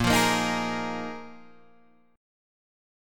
A#7sus4 chord